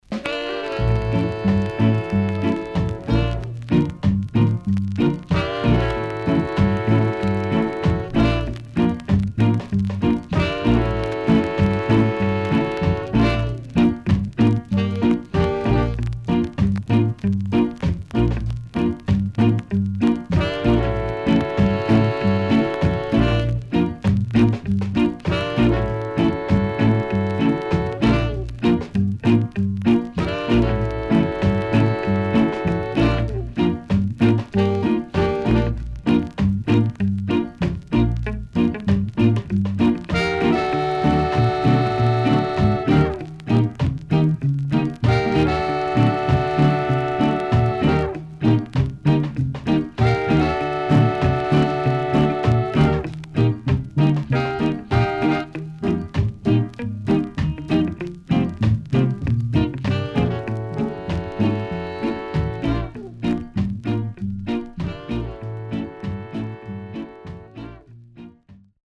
ROCKSTEADY
SIDE A:所々ノイズ入ります。